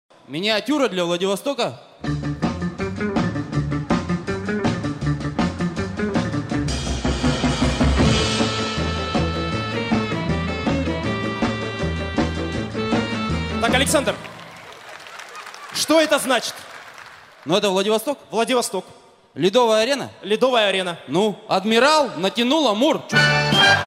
vostochnaya-melodiya.mp3